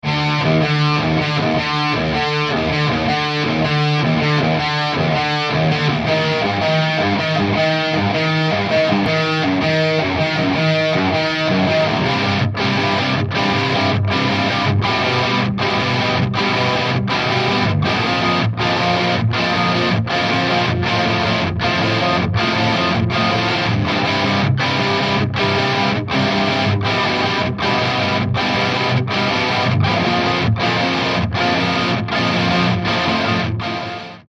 Nahrávací studio v Lipově audio / digital
Dying Passion mají zkreslené kytary natočeny, zítra nás čeká baskytara (baska).
Z jedné strany se na Vás hrne Triple Rectifier a z druhé Boogie Studio Preamp přes Carwin Amplifier. Oba aparáty jedou přes stejný box Mesa Boogie 4x12".